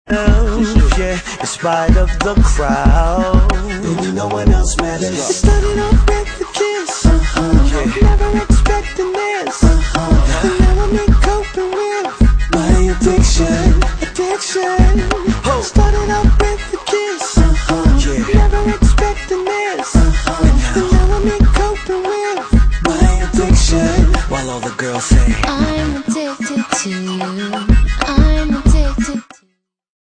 RnB & Garage